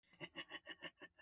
robber.mp3